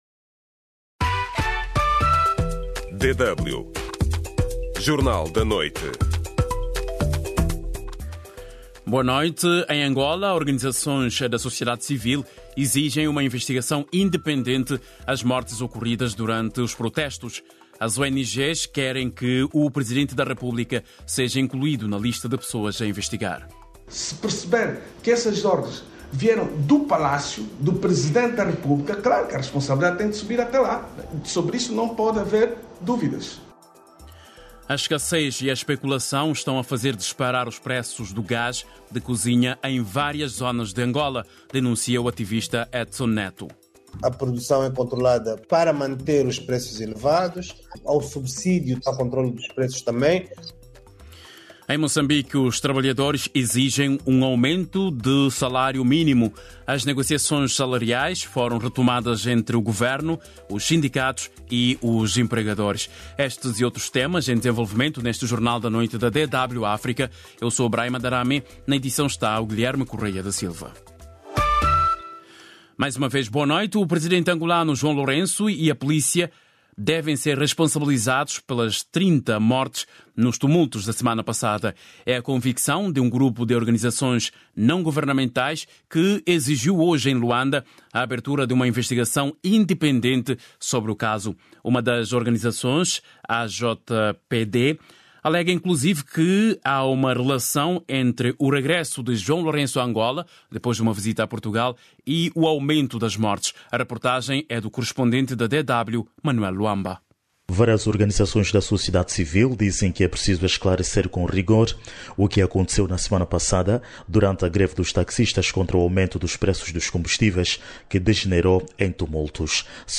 … continue reading 155 bölüm # Notícias # Notícias Diárias # Portugal